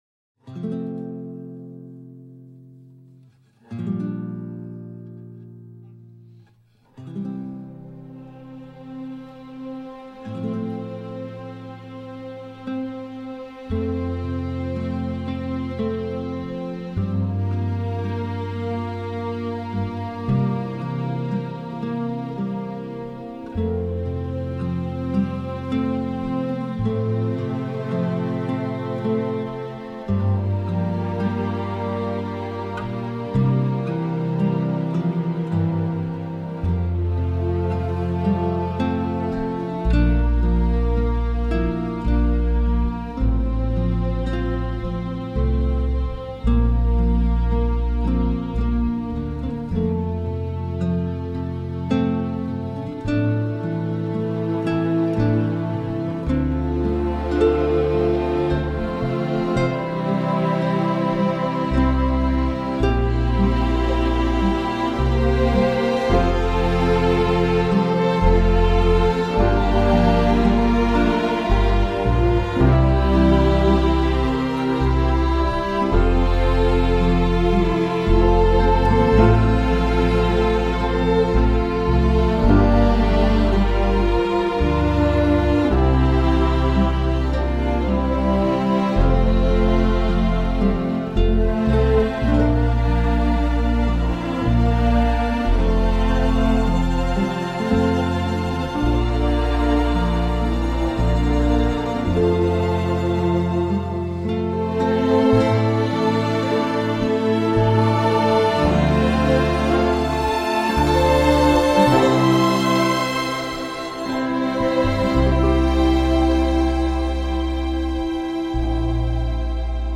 un score aux allures de western champêtre et mélancolique
Dépressif et finalement un petit peu ennuyeux sur la durée.